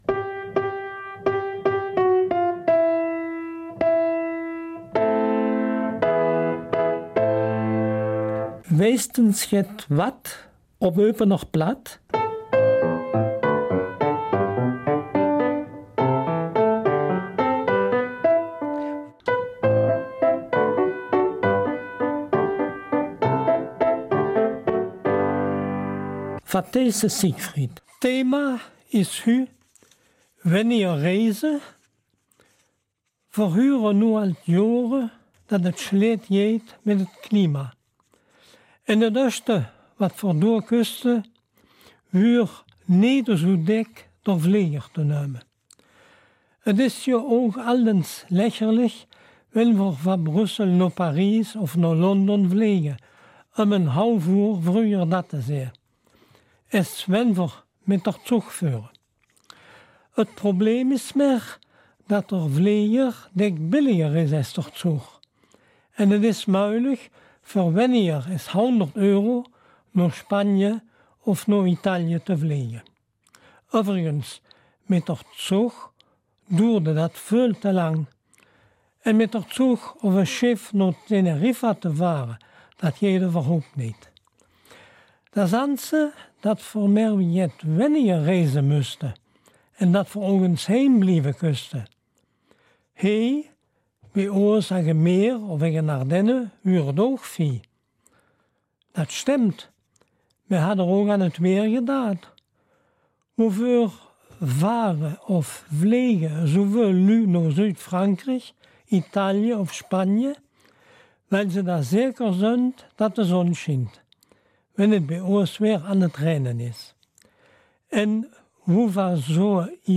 Eupener Mundart - 13. Dezember